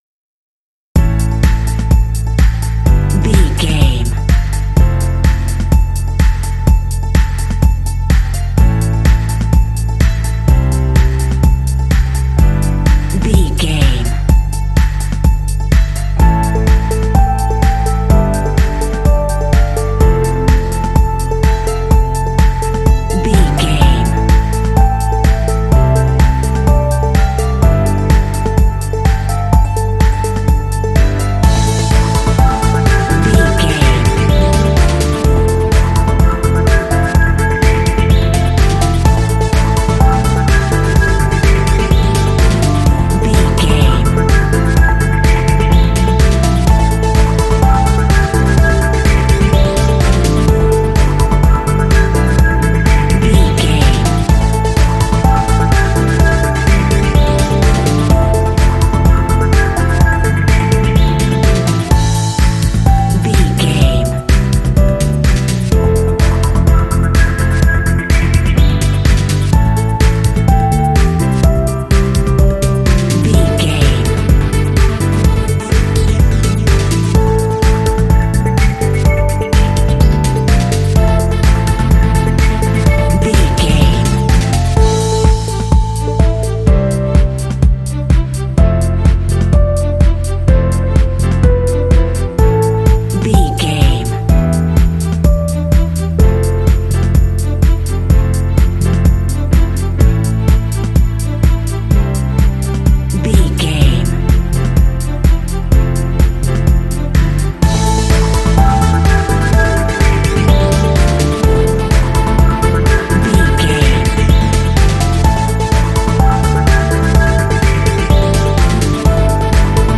Mixolydian
motivational
driving
synthesiser
drums
piano
electric guitar
bass guitar
strings
synth-pop